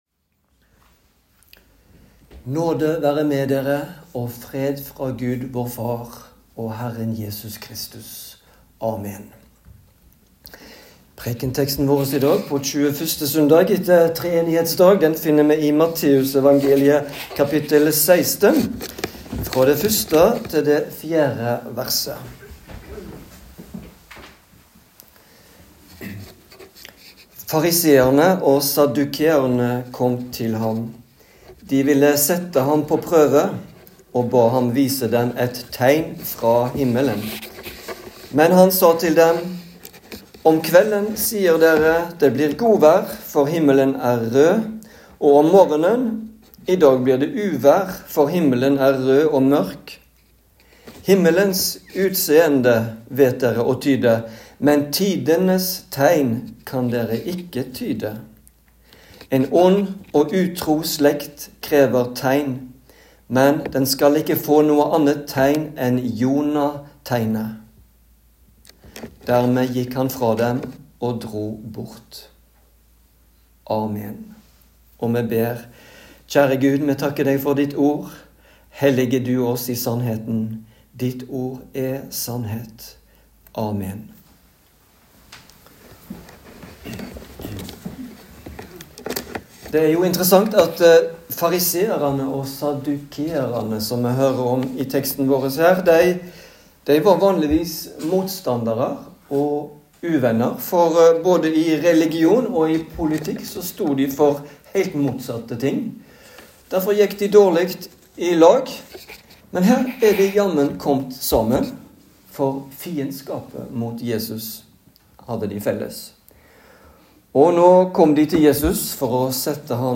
Preken på 21. søndag etter Treenighetsdag